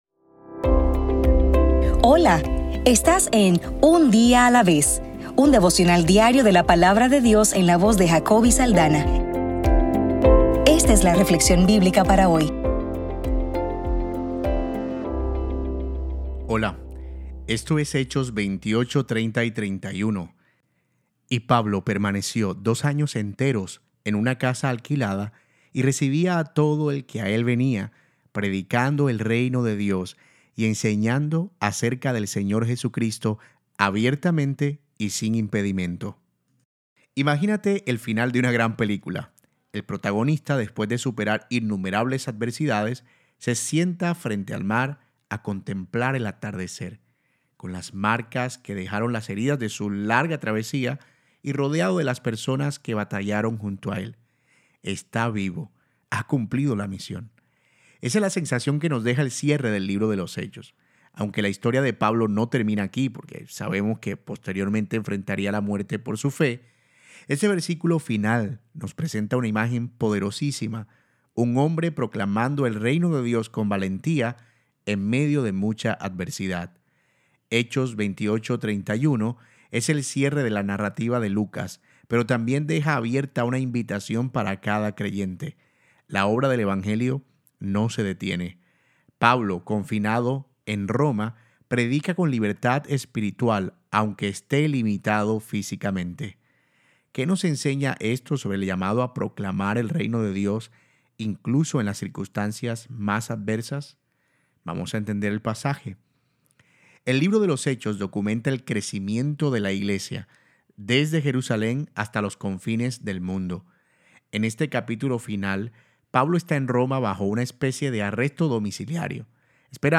Devocional para el 28 de enero